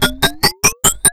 PHASER LP1-R.wav